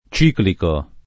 ciclico [ ©& kliko ]